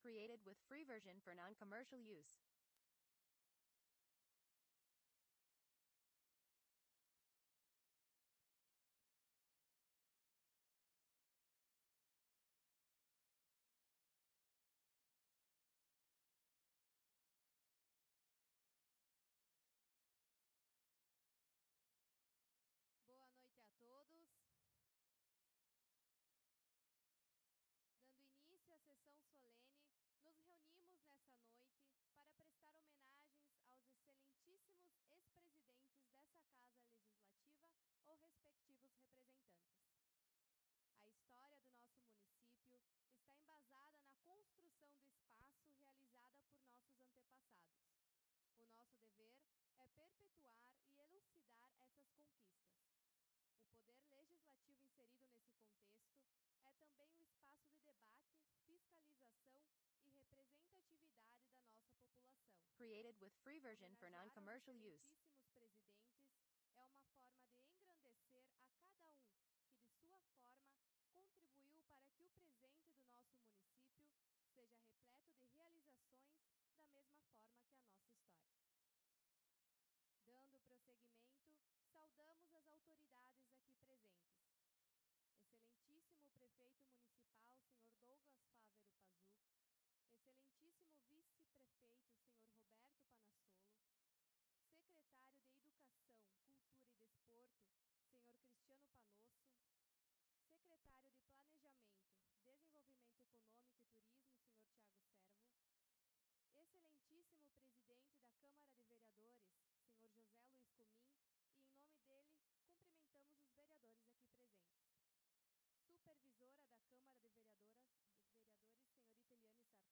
Sessão Solene em homenagem a todos os ex-presidentes e nomeação da Câmara para Câmara de Vereadores 30 de Novembro